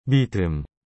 “Mid” é curto e direto, enquanto “eum” é nasal e suave.
믿음.mp3